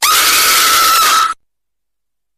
Scary Maze Game Scream Sound